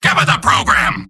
Robot-filtered lines from MvM. This is an audio clip from the game Team Fortress 2 .
{{AudioTF2}} Category:Soldier Robot audio responses You cannot overwrite this file.
Soldier_mvm_jeers09.mp3